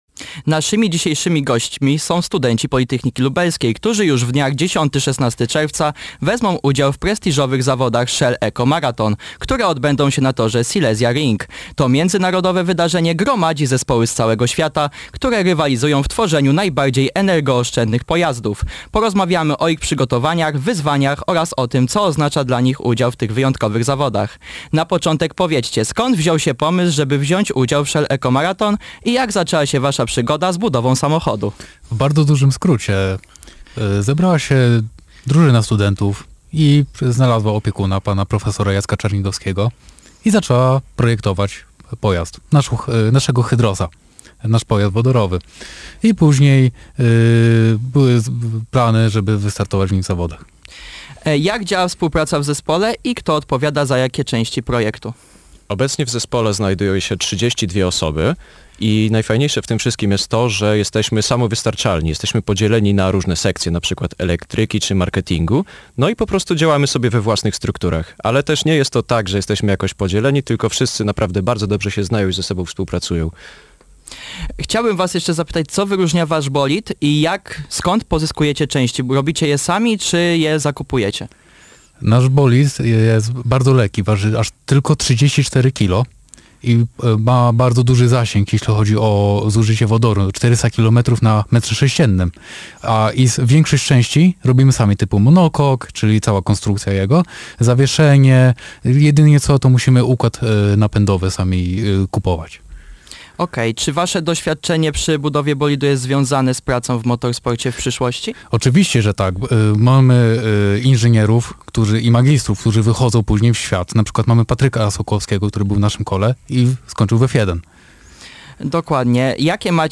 Rozmowa-z-gosciem.mp3